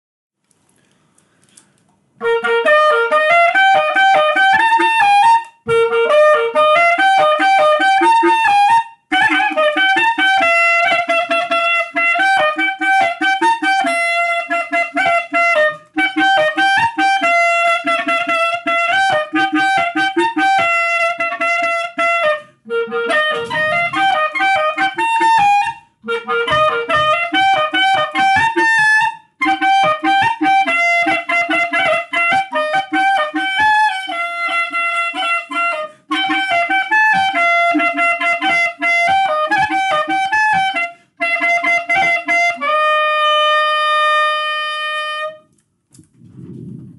Instrumental. Clarinette. Couserans
Aire culturelle : Couserans
Genre : morceau instrumental
Instrument de musique : clarinette
Danse : traversée